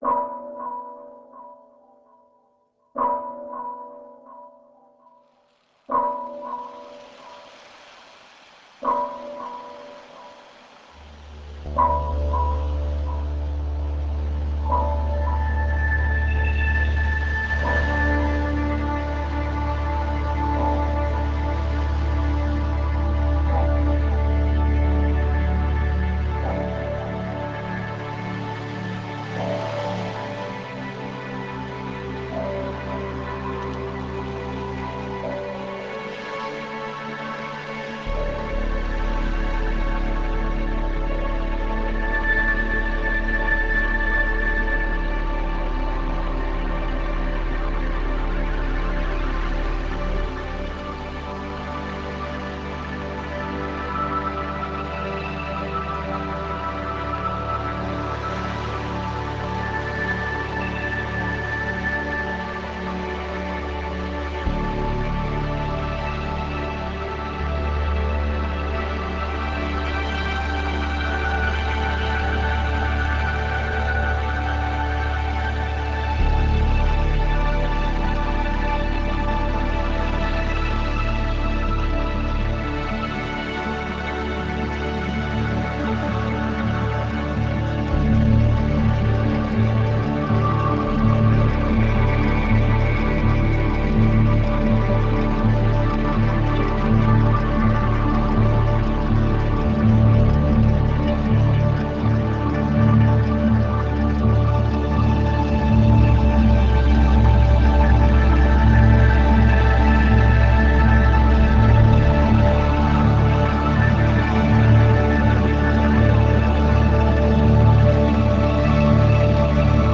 chill-out